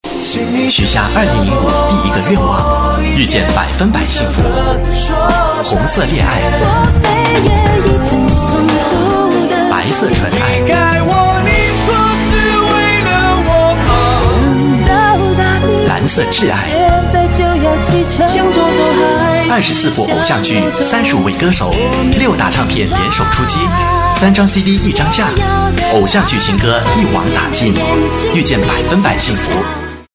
Voice Samples: Mandarin Voice Sample 06
male